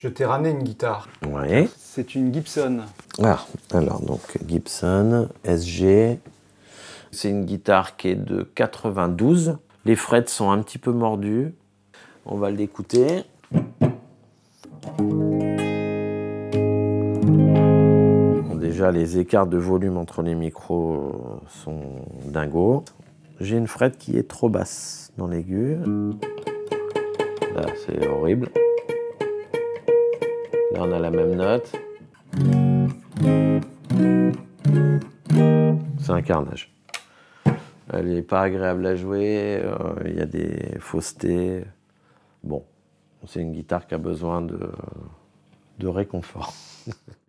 CapioVox crée des contenus sonores authentiques et immersifs, sur mesure, capturant l'émotion et la vérité des lieux.
Mais laissons de côté les projecteurs et le tourbillon des tournées pour vous inviter dans le silence de son atelier, où le temps semble suspendu, où règnent patience et méticulosité.